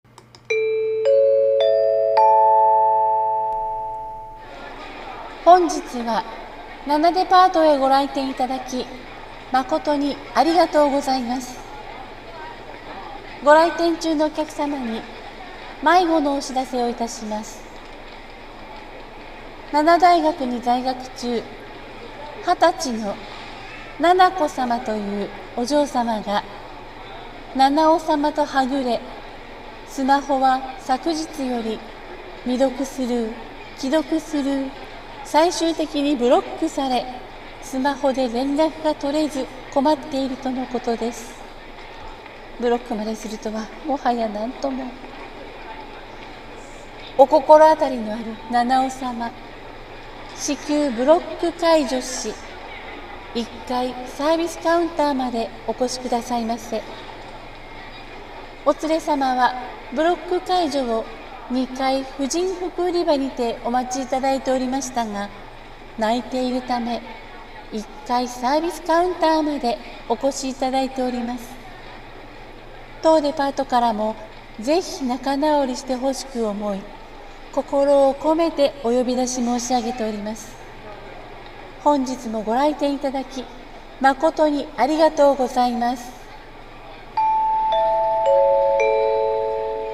【アナウンス風台本】迷子呼出放送~一人声劇~